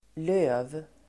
Ladda ner uttalet
löv substantiv, leaf (plur. leaves)Uttal: [lö:v] Böjningar: lövet, löv, lövenSynonymer: bladDefinition: blad på träd och buskarSammansättningar: löv|skog (deciduous forest)